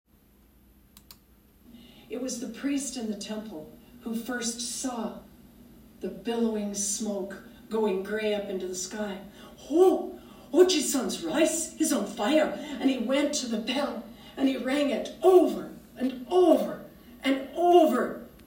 Storytelling
Hamaguchi-priest-saw-fire.-Hesitant.m4a